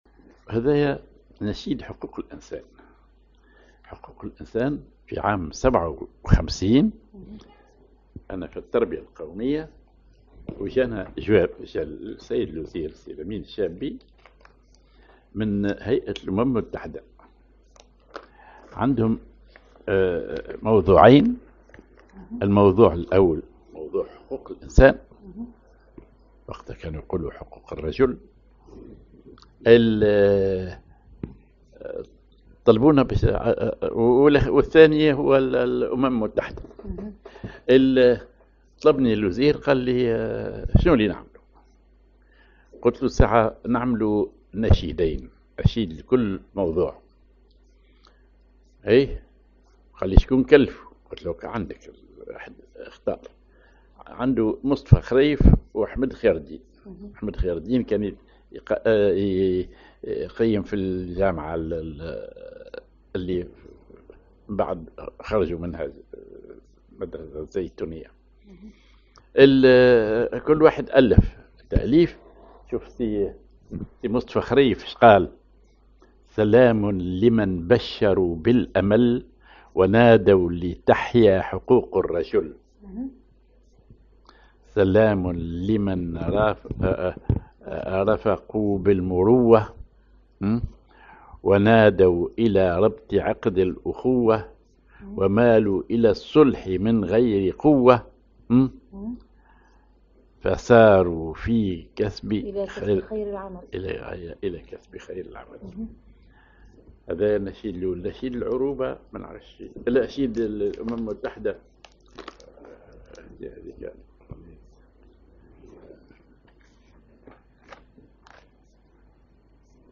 Maqam ar فا كبير (أو جخاركاه على درجة الجهاركاه)
genre نشيد